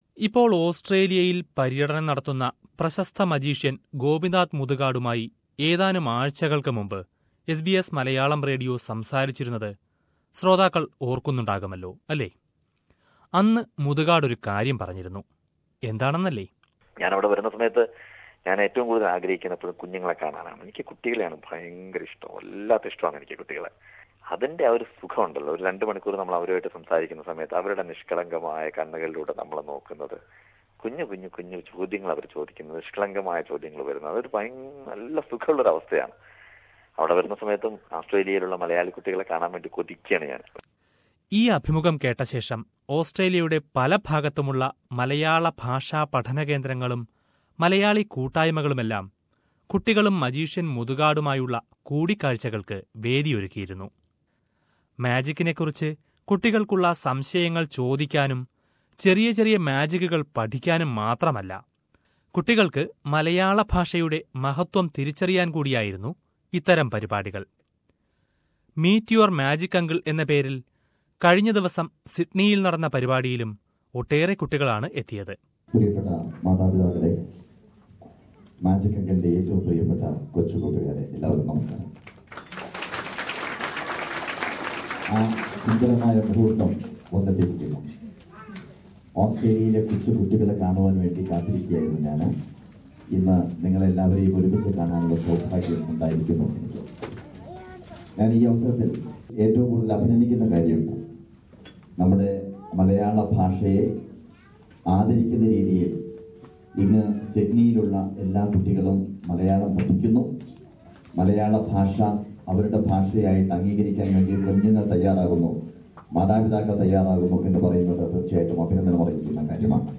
He met the Malayalee kids in various Australia cities and spent time with them answering their doubts about magic. Let us listen to his chat with kids in Sydney.